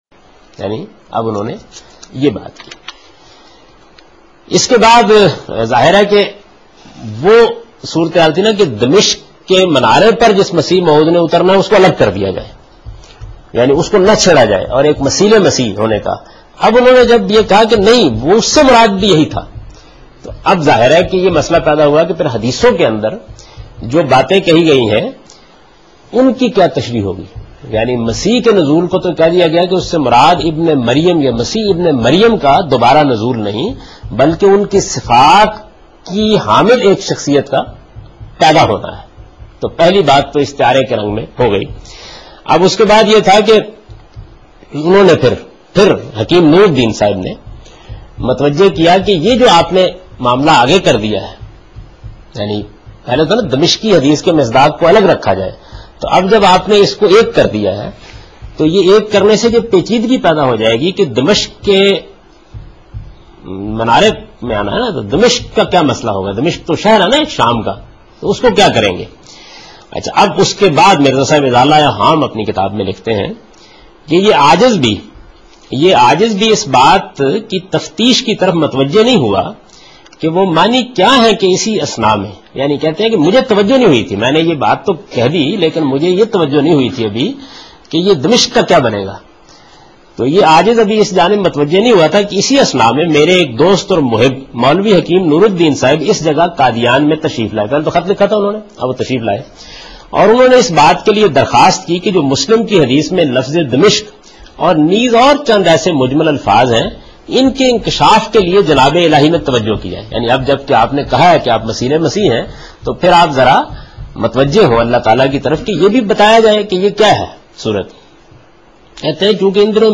Javed Ahmed Ghamidi: From Meezan Lectures